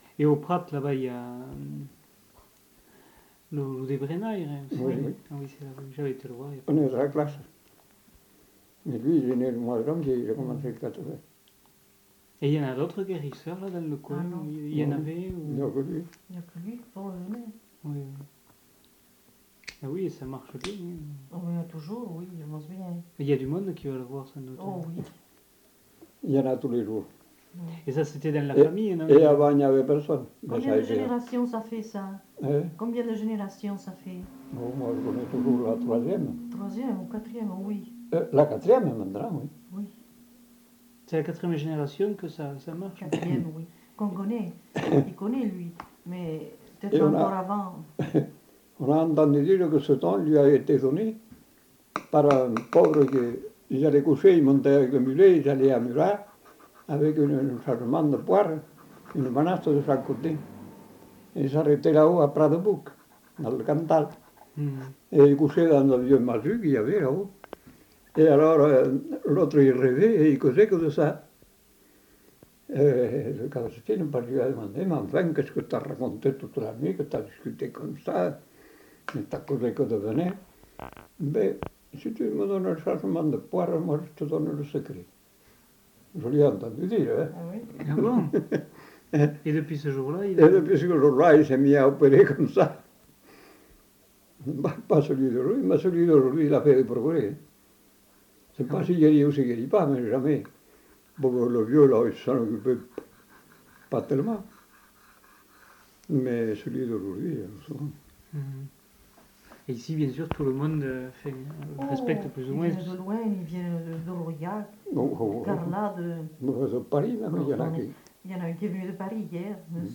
Lieu : Lacroix-Barrez
Genre : témoignage thématique